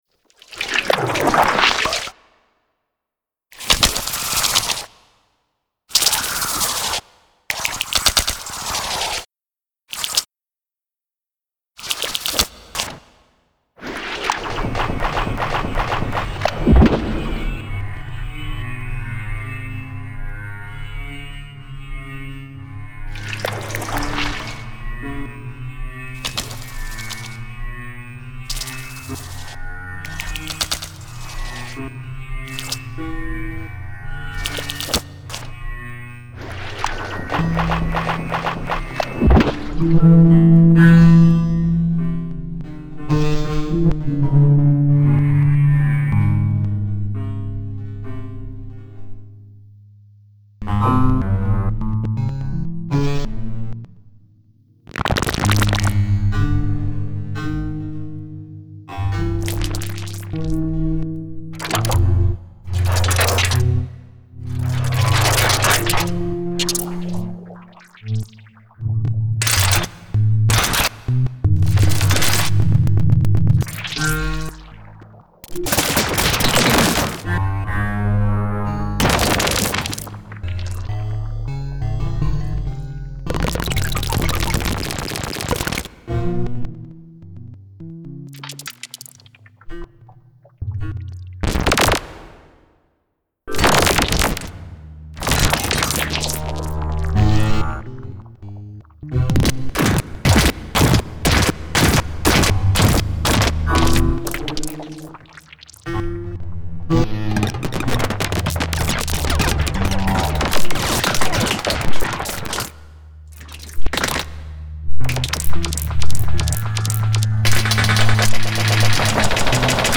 Stubborn. Though internally meditative. Fragments from once consolidated statements.